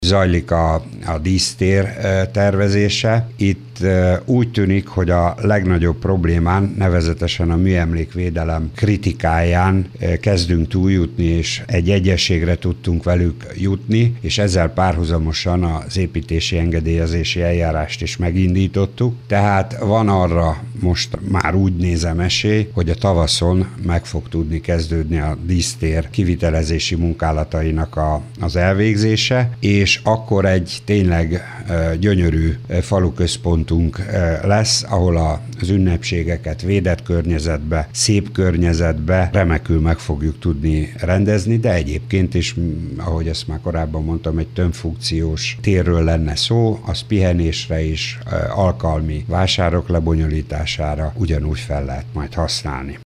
Pulisch József polgármestert hallják.